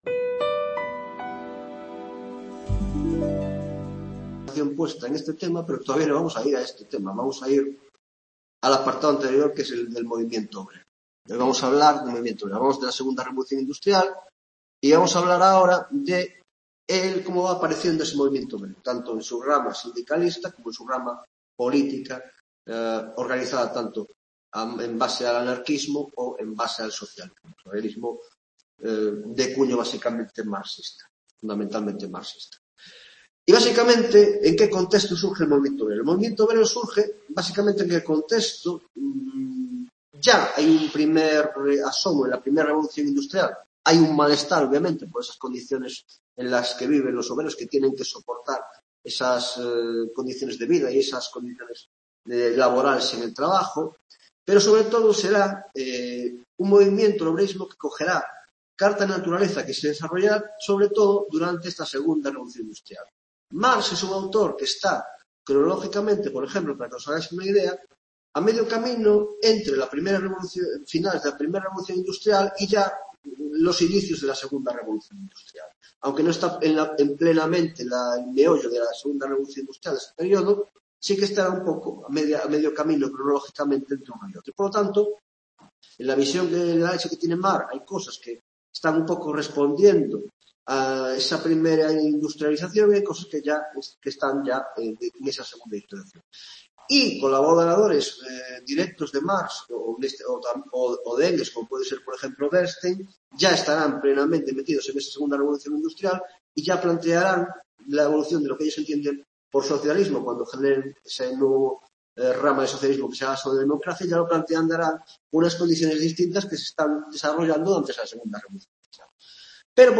8ª tutoria de Historia Contemporánea: El Movimiento Obrero: 1) Sindicalismo, Socialismo y Anarquismo: 1.1) Socialismo Utópico, 1.2) El socialismo Marxista; 1.3) El Anarquismo; 2) El sindicalismo evolución y desarrollo; 3) las Internacionales Obreras (AIT)